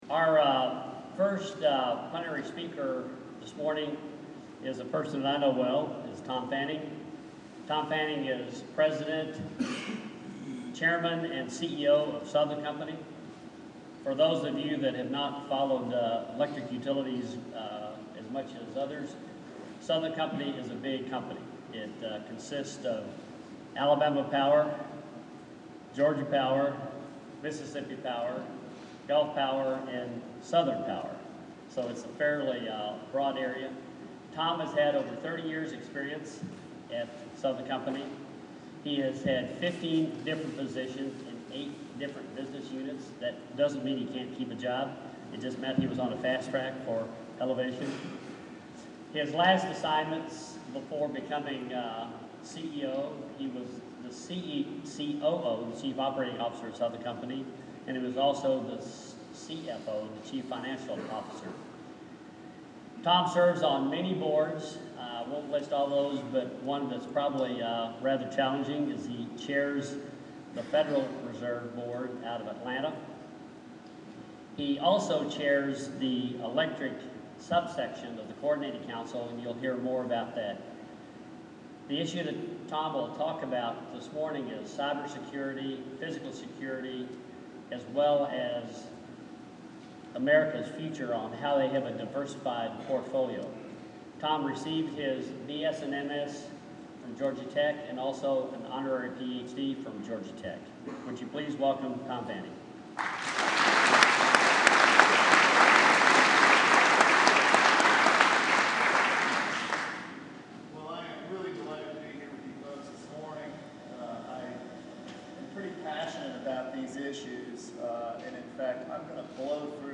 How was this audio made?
Here is a quote from the start of his talk during the ANS 2015 opening plenary session.